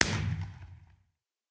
blast_far.ogg